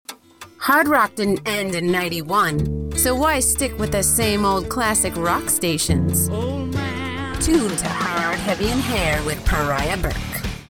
ID-91-Didnt-End-Same-Old-Classic-Rock-Stations-11s.mp3